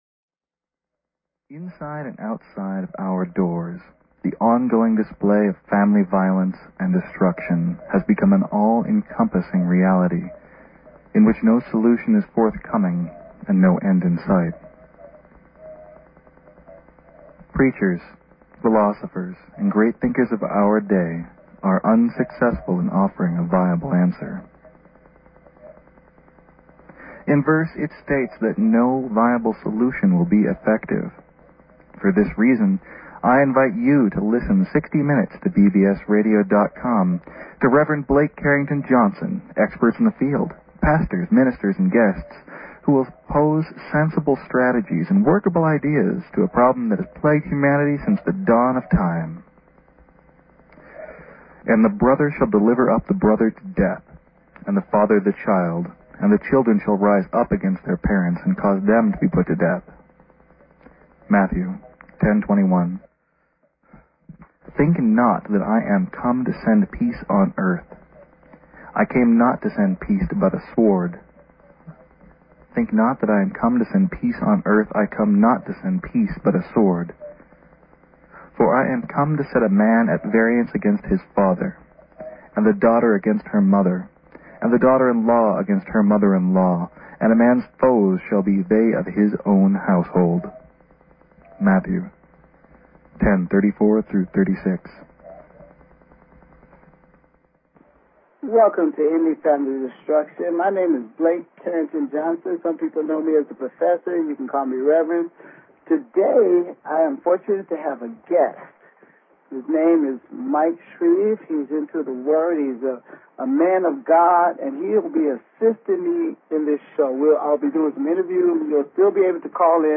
Talk Show Episode, Audio Podcast, Ending_Family_Destruction and Courtesy of BBS Radio on , show guests , about , categorized as